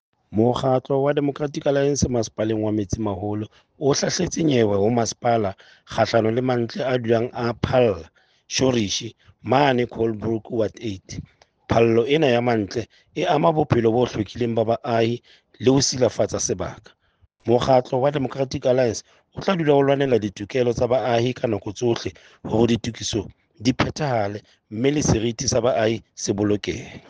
Sesotho soundbite by Cllr Stone Makhema.